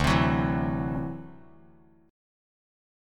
Listen to D9 strummed